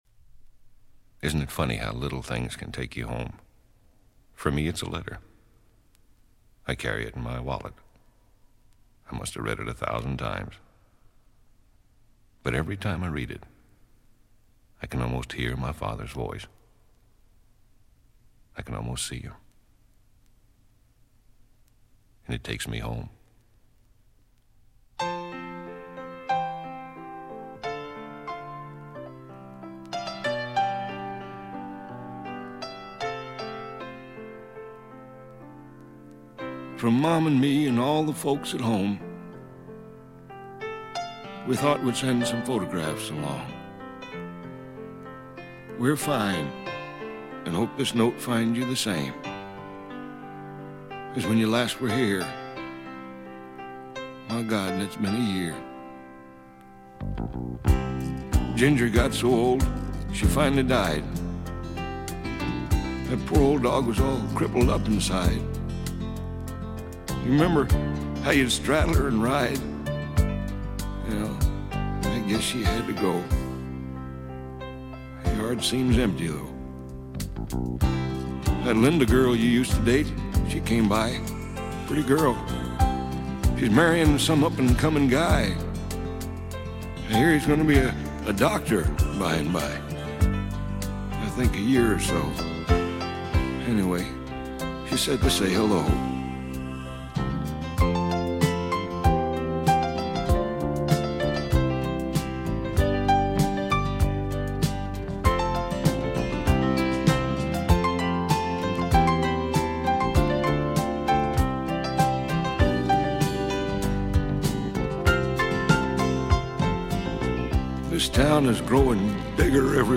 This folk music
(drums)
(piano)
(bass guitar)
(guitar)
(harmonica)
(banjo)
(strings)
(vocals)
Folk music--Iowa